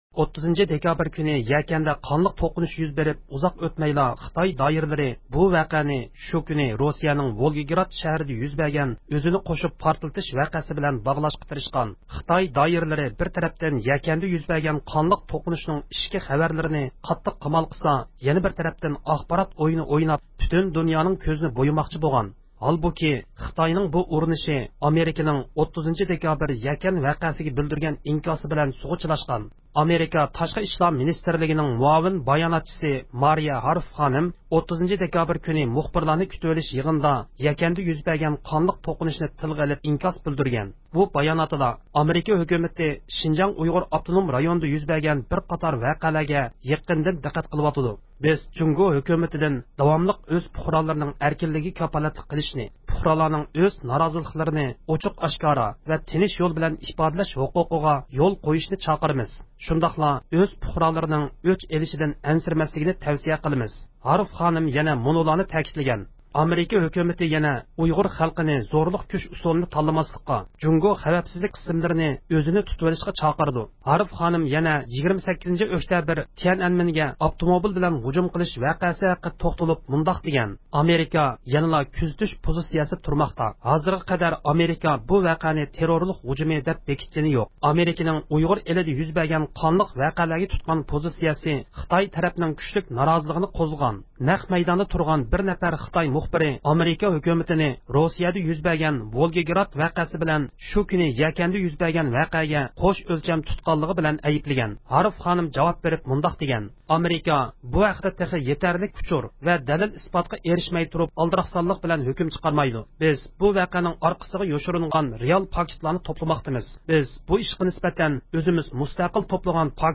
ئۇيغۇر مىللىي ھەرىكىتىنىڭ رەھبىرى، دۇنيا ئۇيغۇر قۇرۇلتىيىنىڭ رەئىسى رابىيە قادىر خانىم زىيارىتىمىزنى قوبۇل قىلىپ، ئامېرىكا باشلىق دۇنيادىكى دېموكراتىيە ۋە ئىنسان ھەقلىرىنى قوللايدىغان ئەللەرنىڭ خىتاينىڭ ئاخبارات ئويۇنىغا ھەرگىز ئەگەشمەيدىغانلىقىغا ئىشىنىدىغانلىقىنى بىلدۈردى.